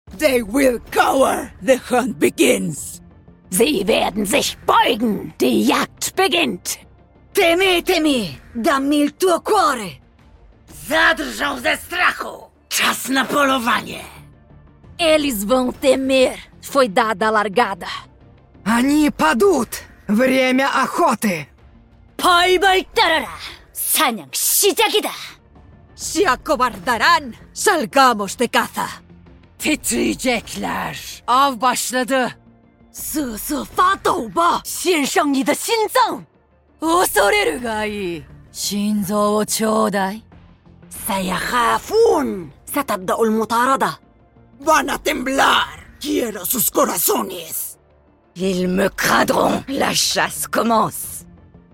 All Brimstone Ultimate Voice Lines sound effects free download
All Brimstone Ultimate Voice Lines in Every Language | Valorant